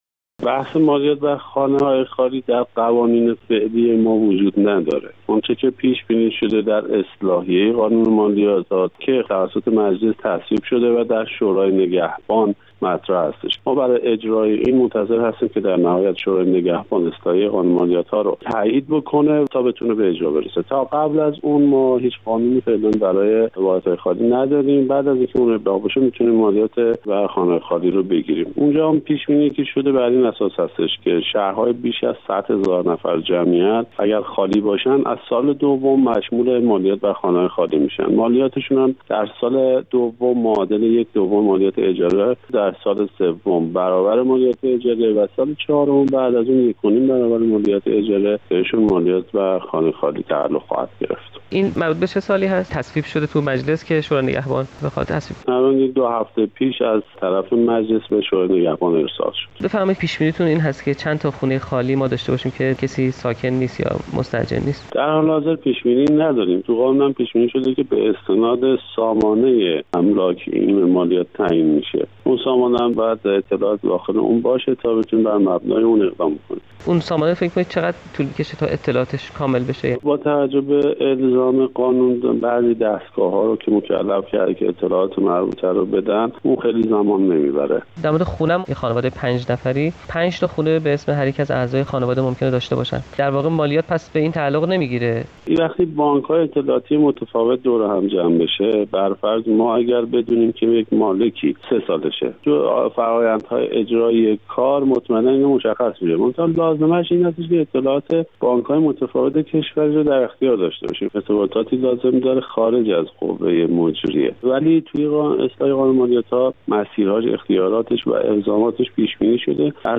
خبرگزاری تسنیم:با وجود اینکه قانون اخذ مالیات از خانه های خالی در مجلس شورای اسلامی تصویب شده، سازمان امور مالیاتی کشور می گوید هنوز قانونی در این خصوص به دستش نرسیده،گفتگوی پیک بامدادی با آقای وکیلی معاون سازمان امور مالیاتی منتشر می شود.